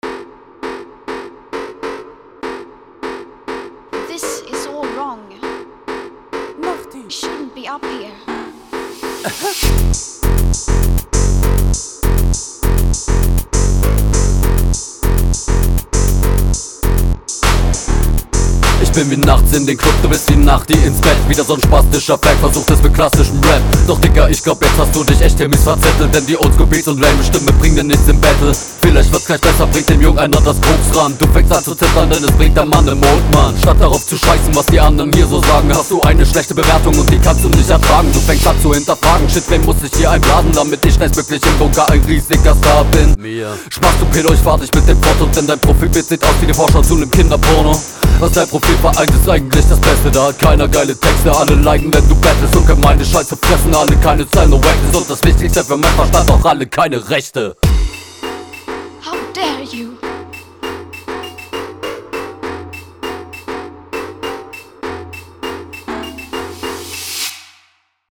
Battle Runden
Beat ist nice, gerne an mich senden haha.